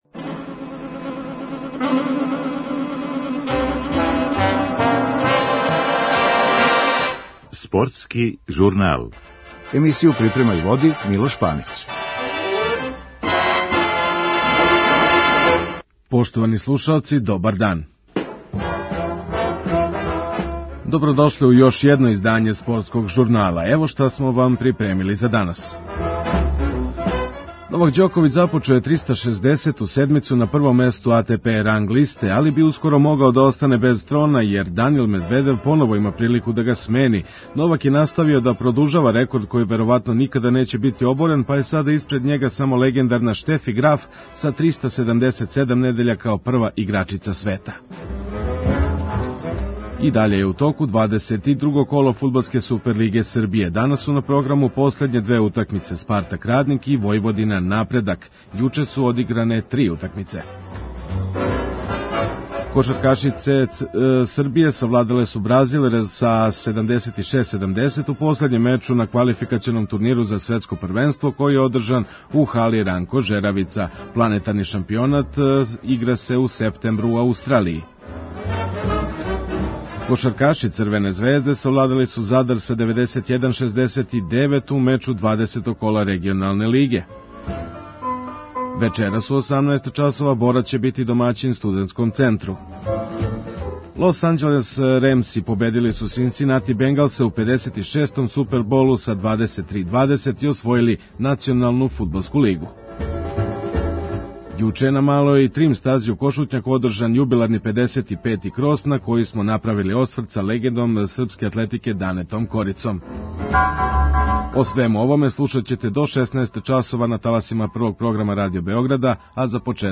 Чућете изјаве после меча наше селекторке Марине Маљковић